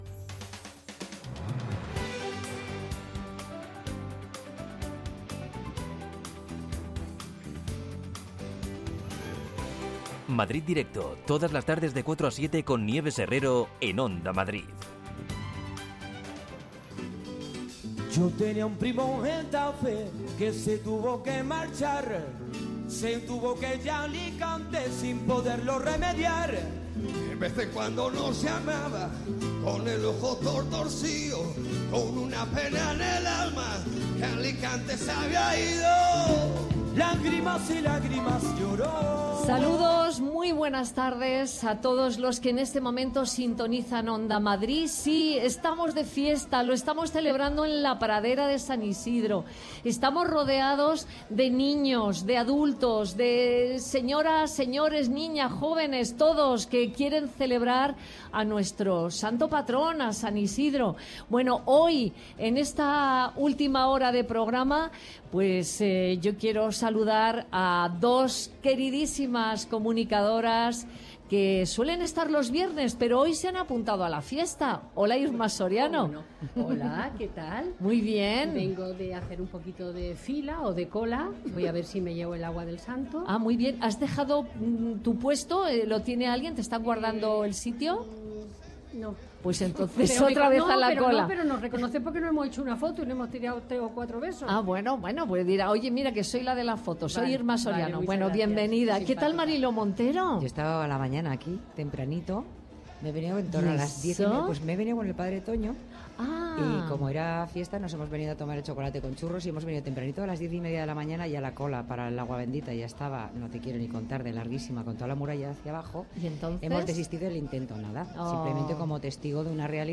Nieves Herrero se pone al frente de un equipo de periodistas y colaboradores para tomarle el pulso a las tardes.
La primera hora está dedicada al análisis de la actualidad en clave de tertulia.